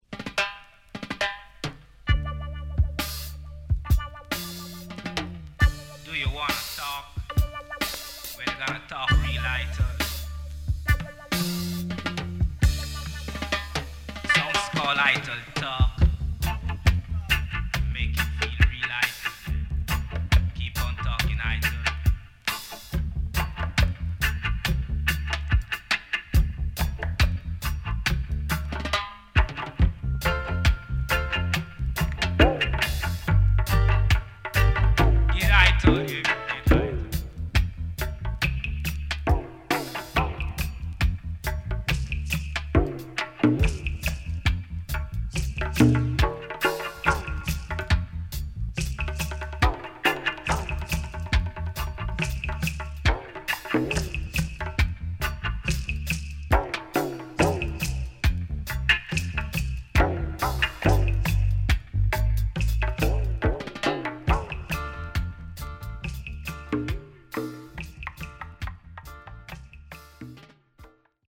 SIDE A:少しノイズ入りますが良好です。